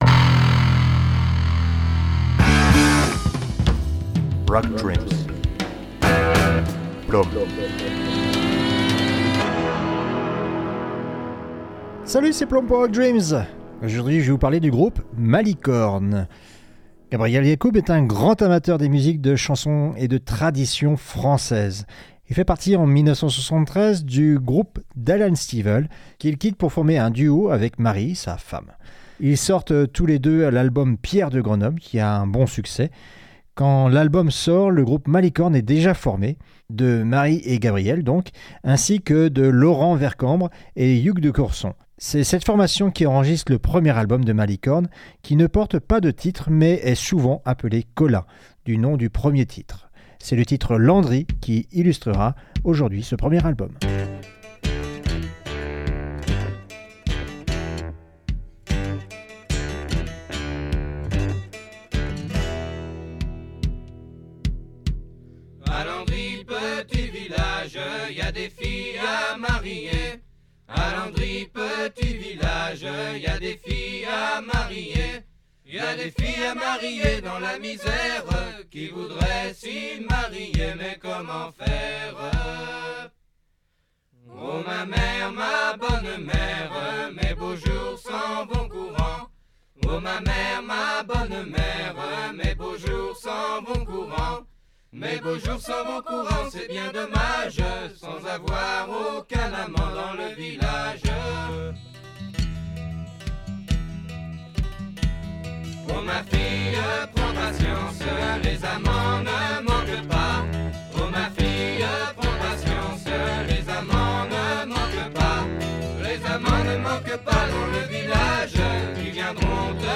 {Folk / Trad}